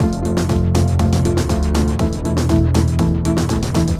Gear Up (Full) 120BPM.wav